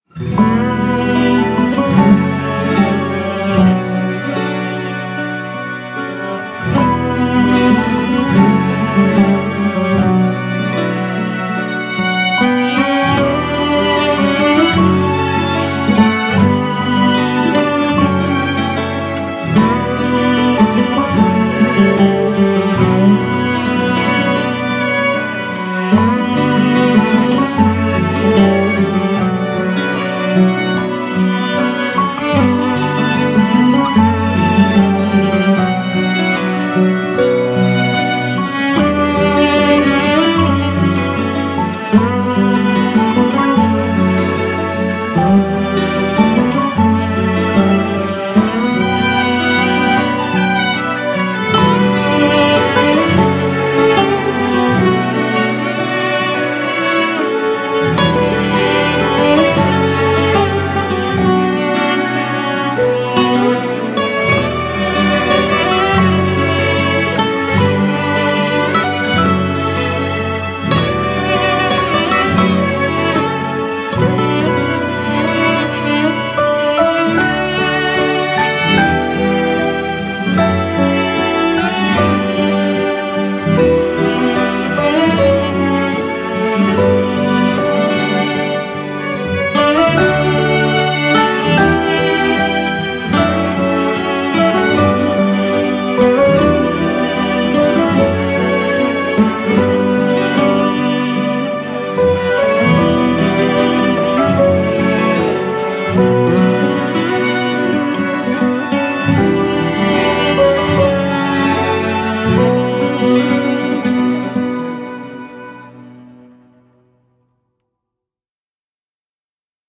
duygusal hüzünlü rahatlatıcı fon müziği.